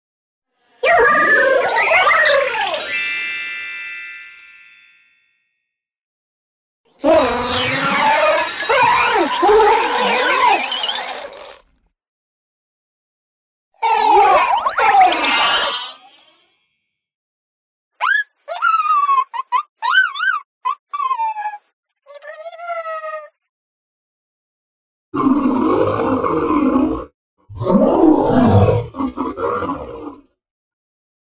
[角色类语音]